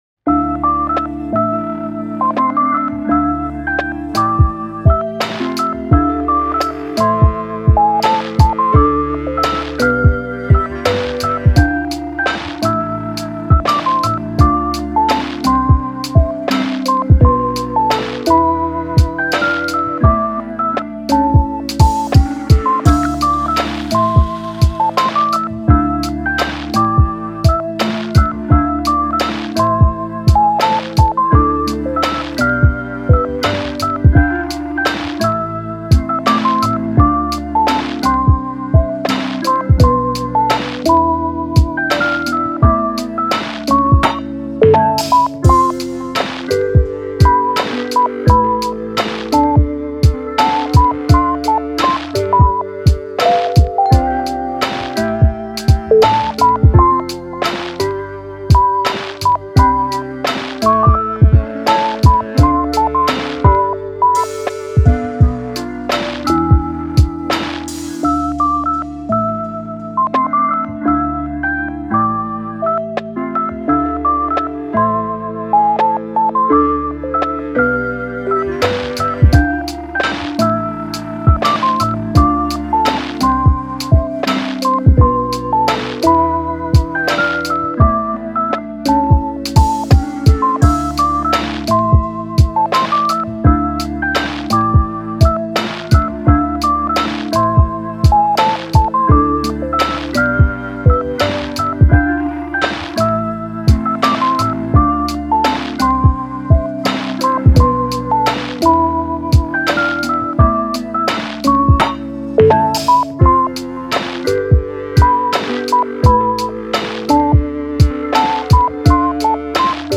メロウ・切ない